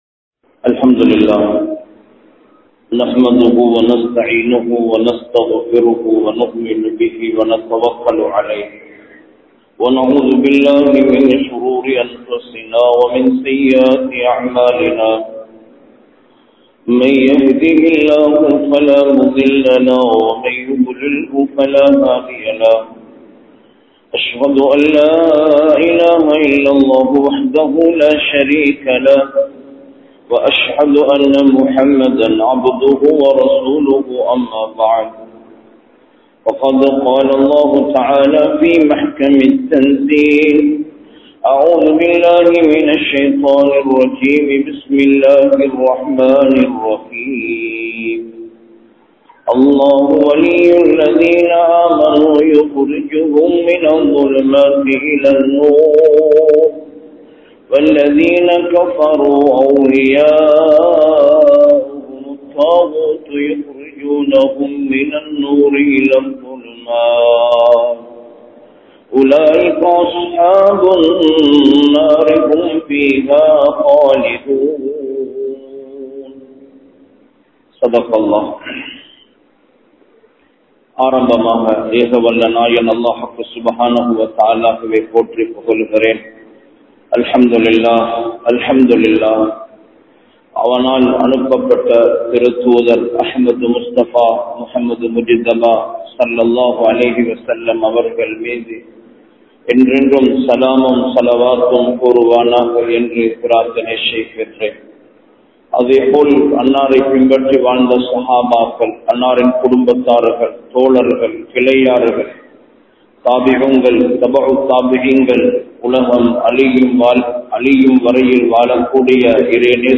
சுவனத்தைத் தடுக்கும் கடன் | Audio Bayans | All Ceylon Muslim Youth Community | Addalaichenai
Welipitiya, Grand Jumua Masjidh